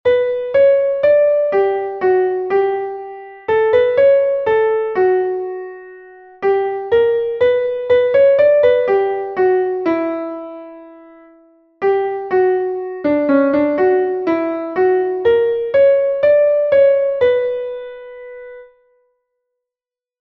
Entoación a capella
entonacioncapela10.1.mp3